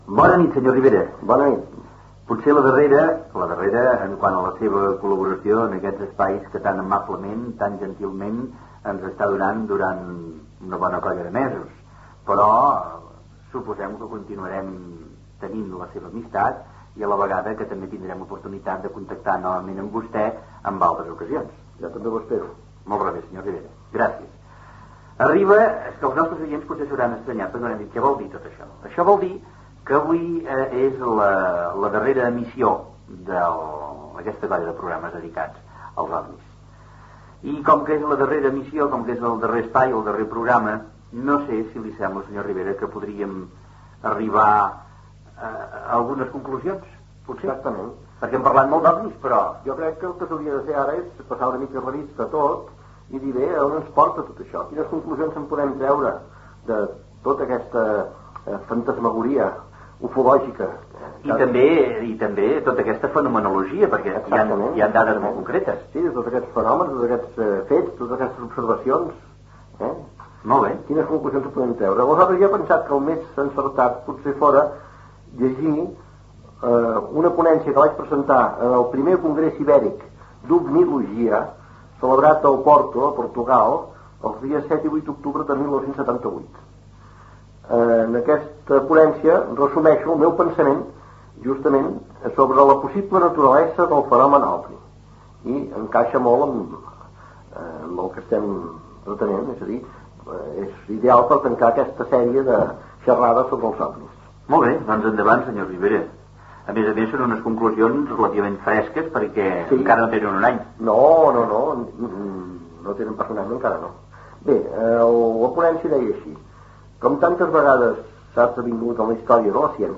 Últim dels espais dedicats al fenòmen OVNI amb Antonio Ribera, qui llegeix un fragment de la ponència que va presentar al Primer Congreso Ibérico de Ovnilogía, celebrat a Oporto (Portugal), el mes d'octubre de 1978.
Info-entreteniment
FM